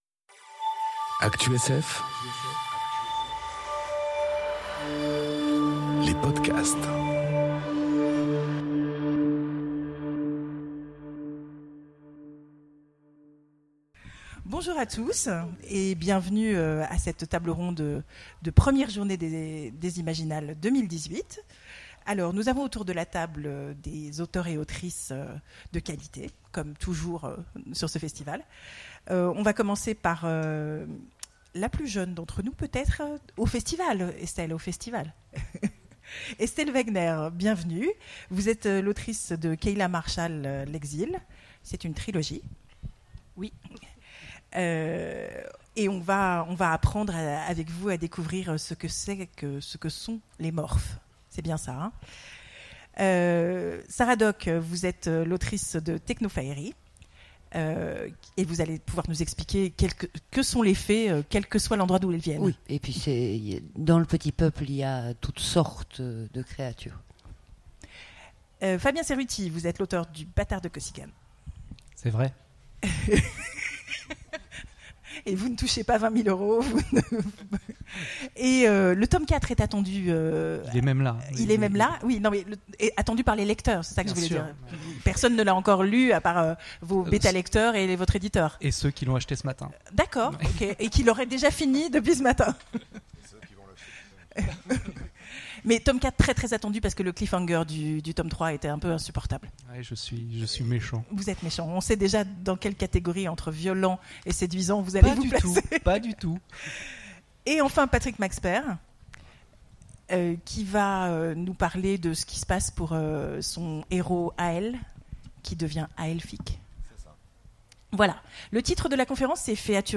Conférence Fées et autres créatures : violentes ou séduisantes ? enregistrée aux Imaginales 2018